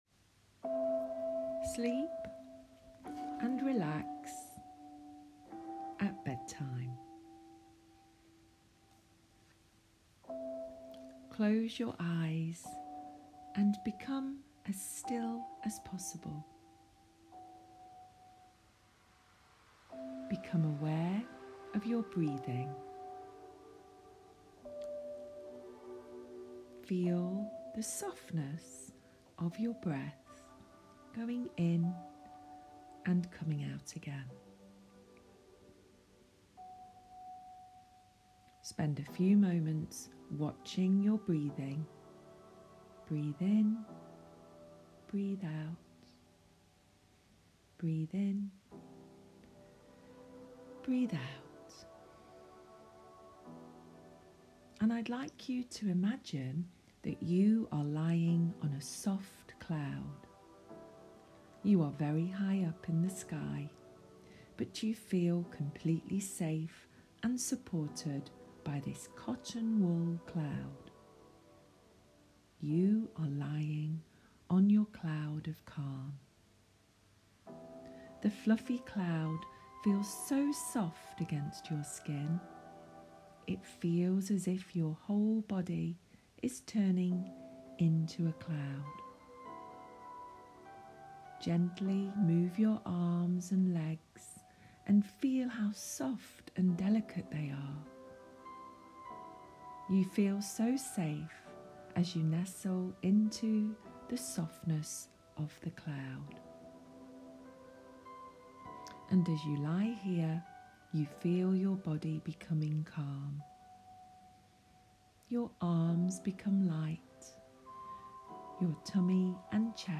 Calm-and-Relax-for-Bedtime.m4a